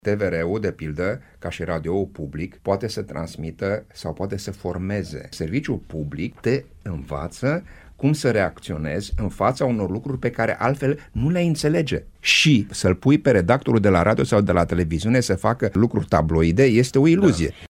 AUDIO / Dezbatere la Radio Timişoara despre propunerea de modificare a Legii 41 şi rolul formativ al serviciilor publice de radio şi televiziune